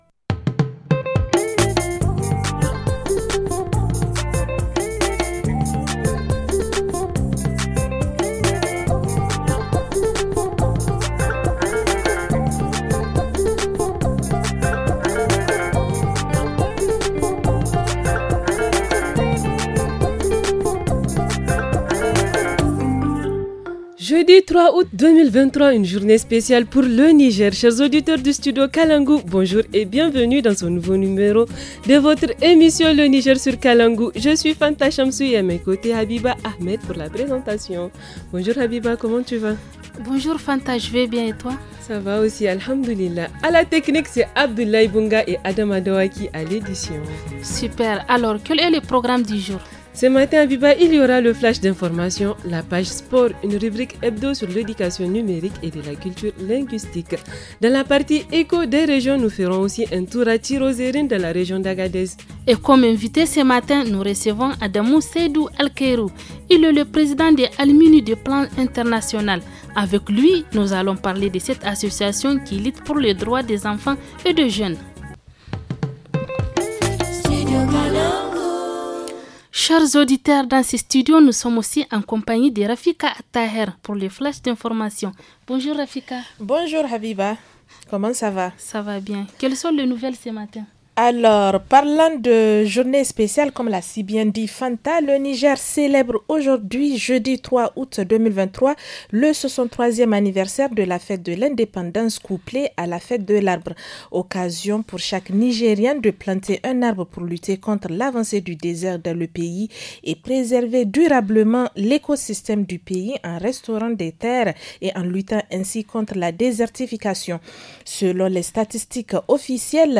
– Reportage en Région : La route Tchirozérine Arlit dégradée ;